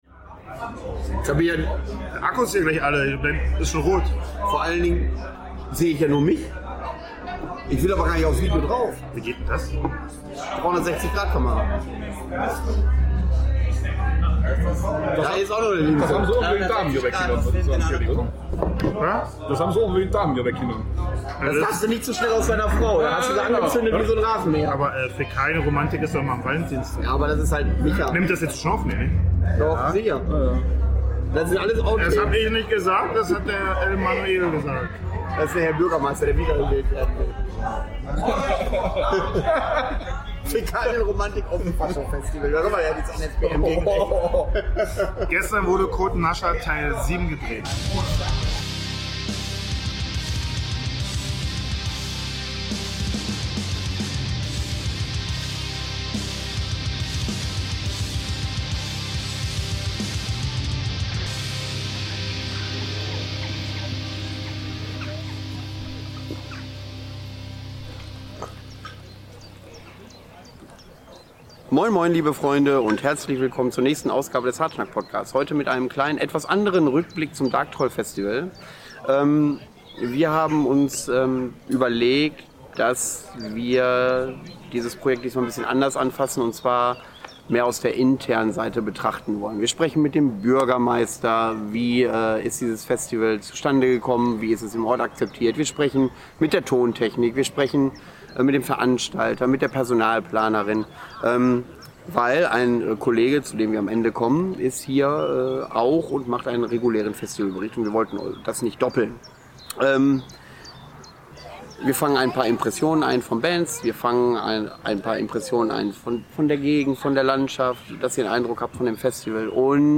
Dark Troll Festival 2025: wir blicken hinter die Kulissen und sprechen mit Verantwortlichen und langjährigen Mitarbeitern und Freunden des Festivals.
Hartschnack - der Extremmetal Podcast - jeden 2. Sonntag.